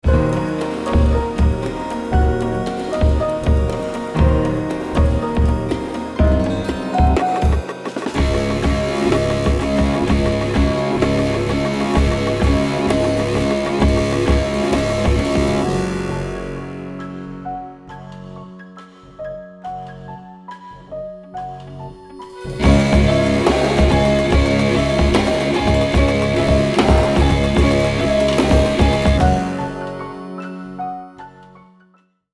I recorded piano, and drums, guitar, and bass.
The snarly bass begged for crash cymbals, which screamed for icy piano… and the song sort of accidentally wound up with a tacky Nu Metal breakdown in the middle.
jerk-nu-metal.mp3